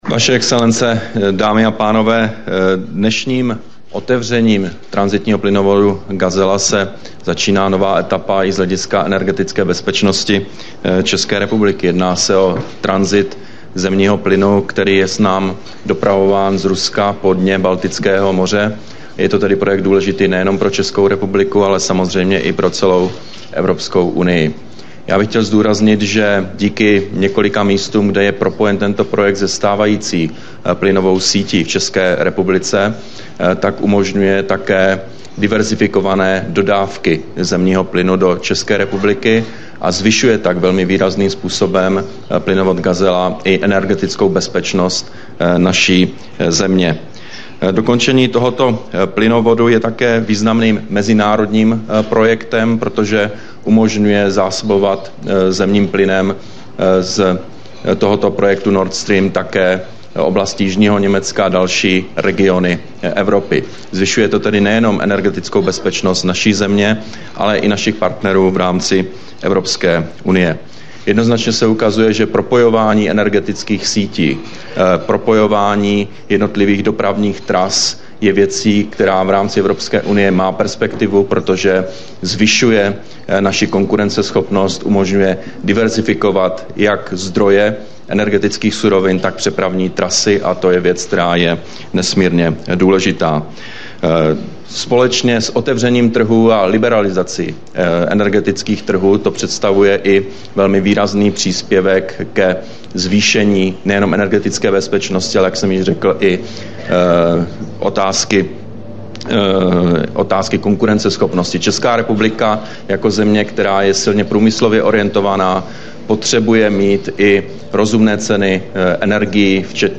Projev remiéra Petr Nečase při slavnostním zahájení provozu plynovodu Gazela, 14. ledna 2013